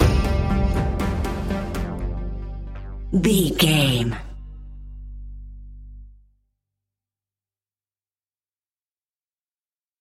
Aeolian/Minor
ominous
dark
haunting
eerie
synthesiser
drum machine
horror music